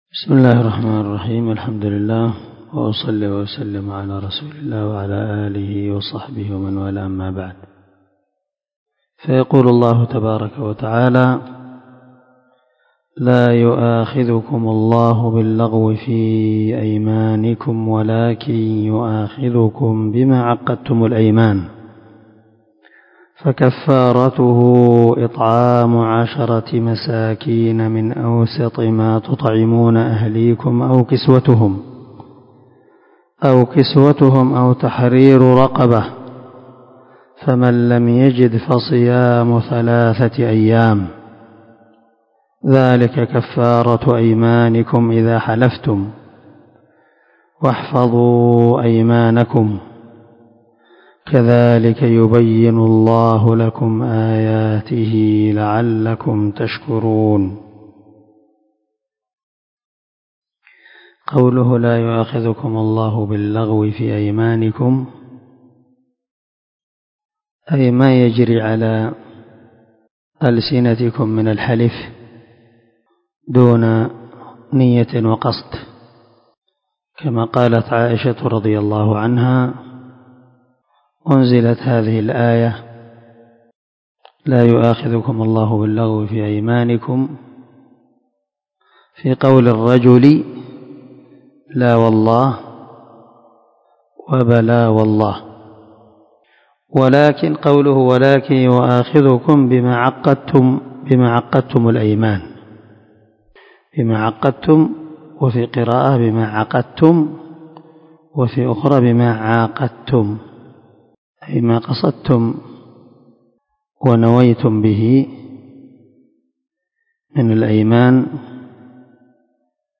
382الدرس 48 تفسير آية ( 89 ) من سورة المائدة من تفسير القران الكريم مع قراءة لتفسير السعدي